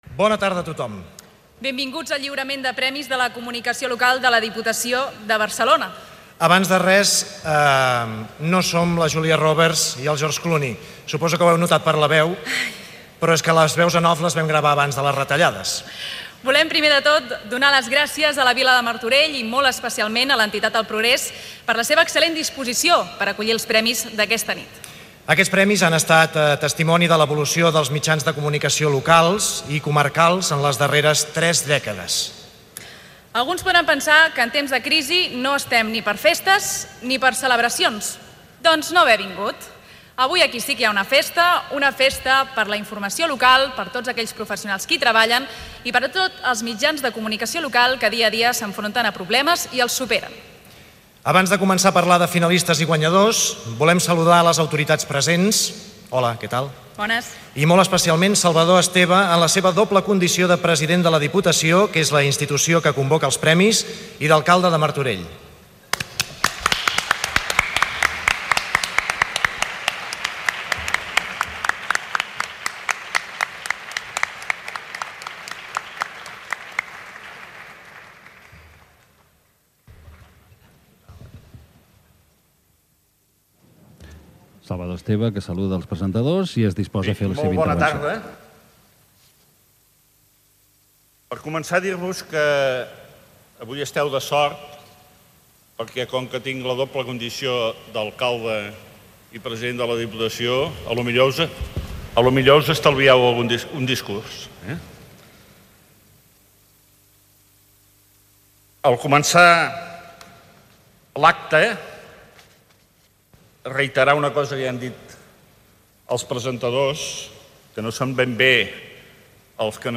Salutació dels presentadors de la gala de lliurament dels 31 Premis de la Comunicació Local 2011 des del Teatre Progrés a Martorell. Fragment del parlament del president de la Diputació i alcalde de Martorell, Salvador Esteve.